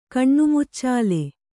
♪ kaṇṇu muccāle